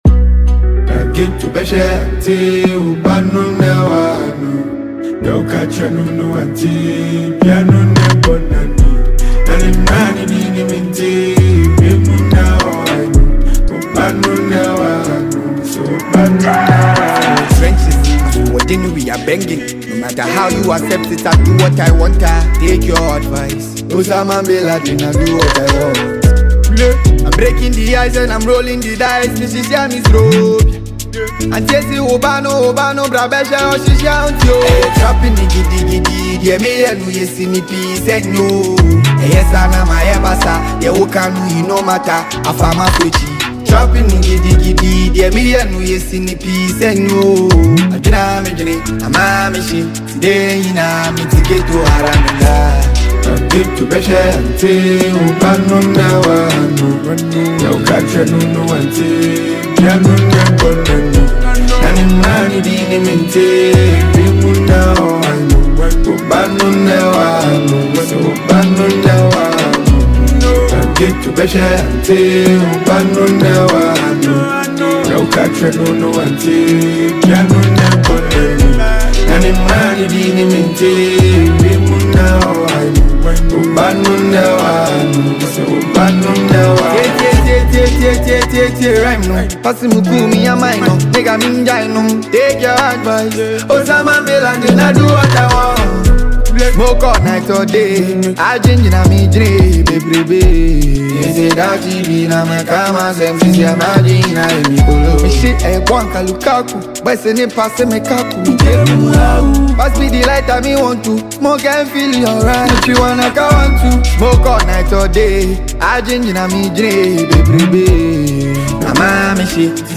The song blends melody, storytelling, and catchy rhythms
With its strong delivery and meaningful lyrics
Afrobeat